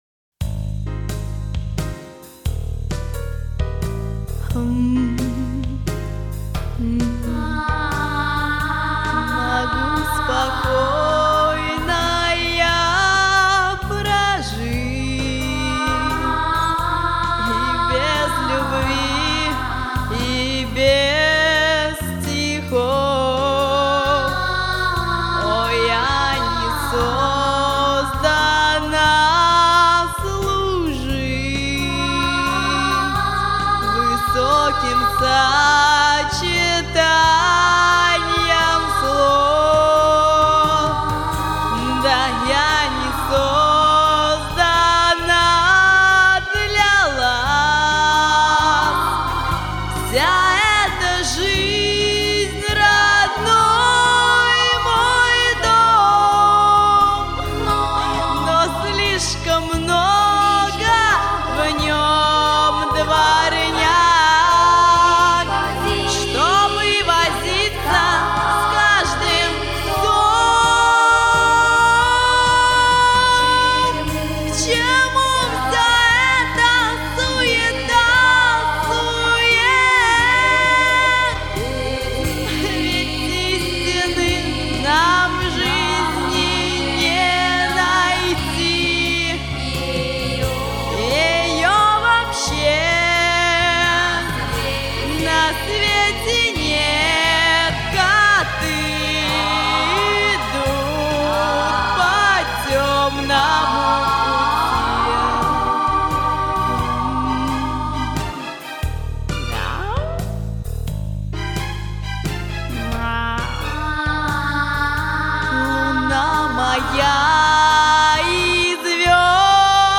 февраль 1999, Гимназия №45